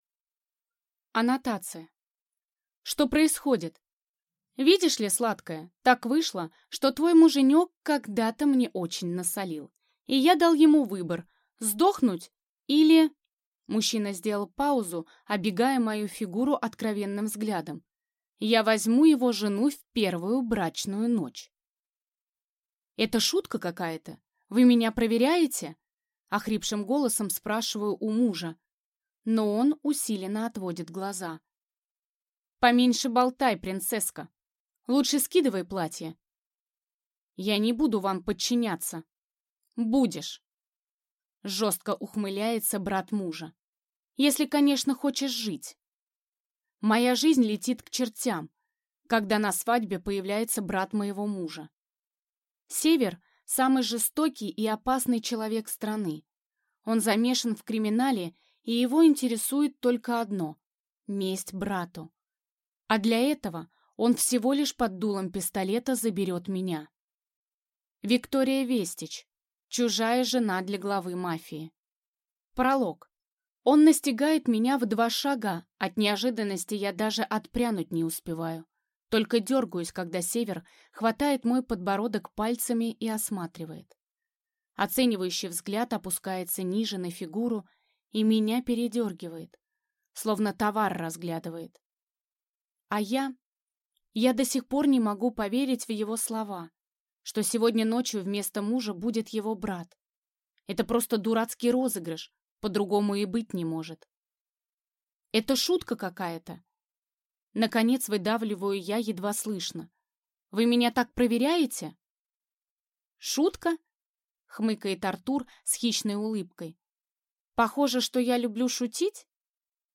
Аудиокнига Чужая жена для главы мафии | Библиотека аудиокниг